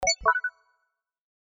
MoonJoinSFX.mp3